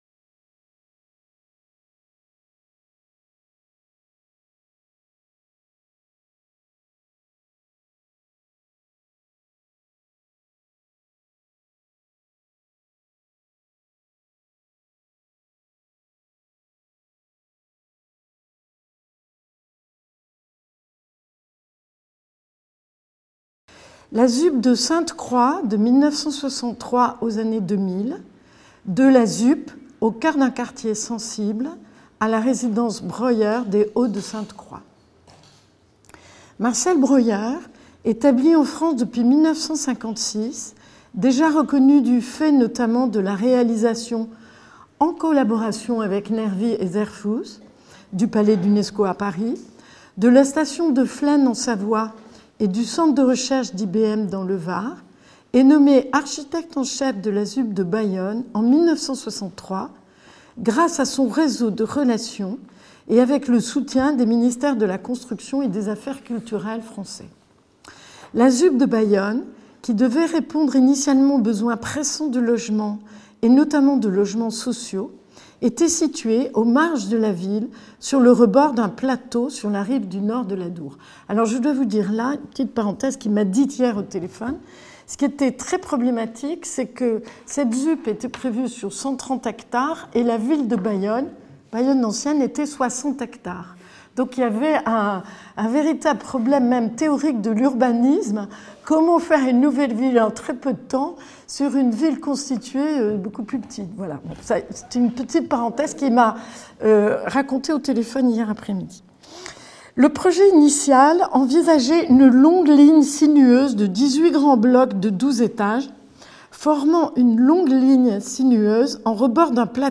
lit le résumé de la conférence